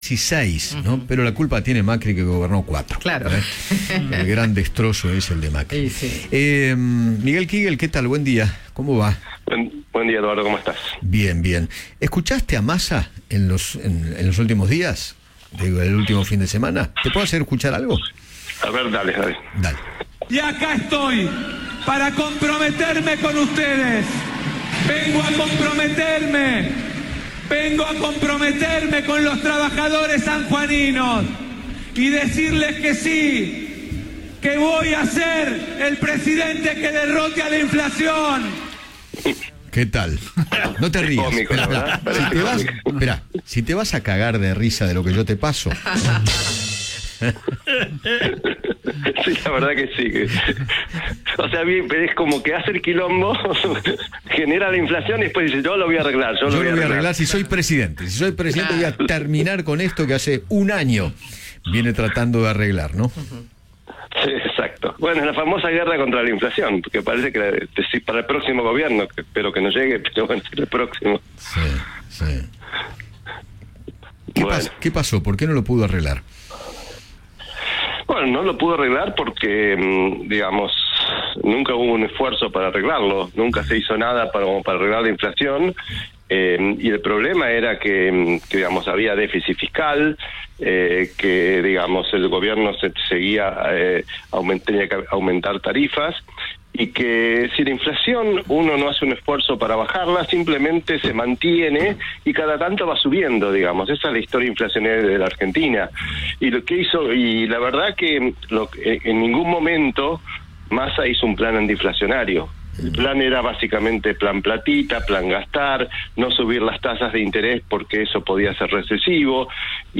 El economista Miguel Kiguel conversó con Eduardo Feinmann sobre los dichos de Sergio Massa y analizó el presente financiero del país.